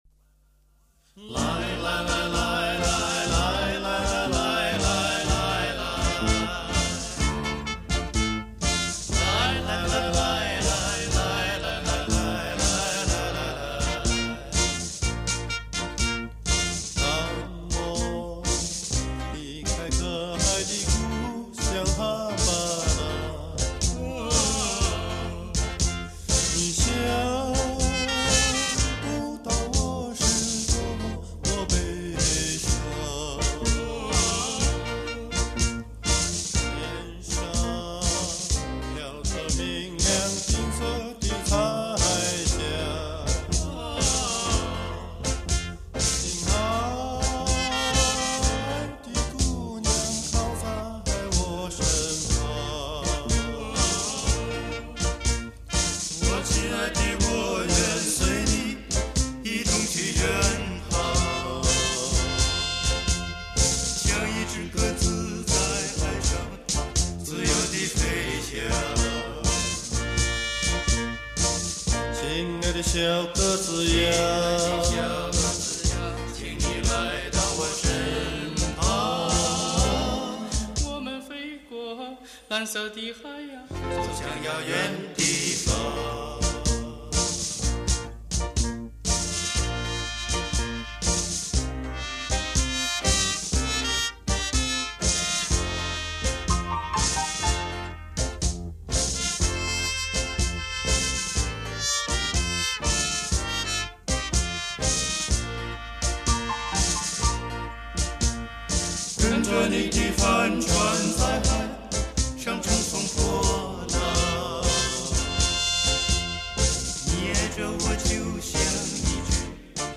男声四重唱
本专辑收录了50-60年代流传于大陆的外国民歌经典，有俄罗斯、美国、古巴、日本等国的优秀民歌，旋律多样，曲调动听 怀旧!